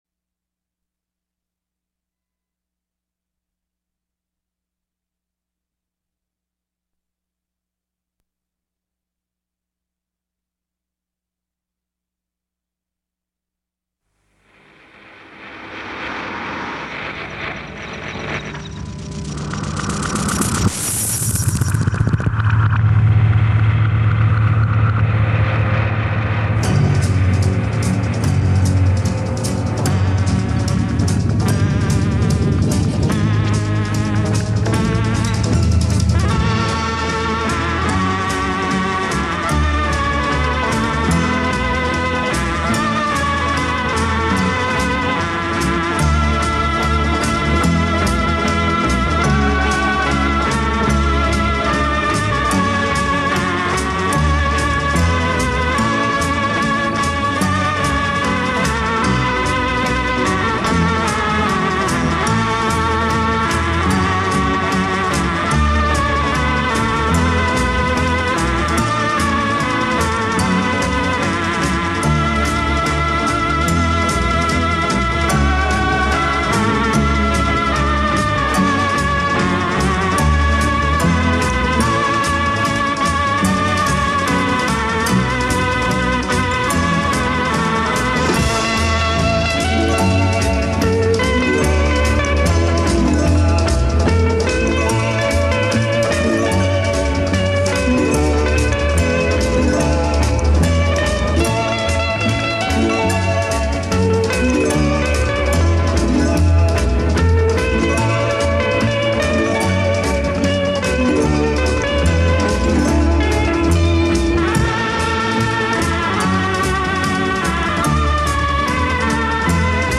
Like a mix-tape on your radio!